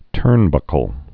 (tûrnbŭkəl)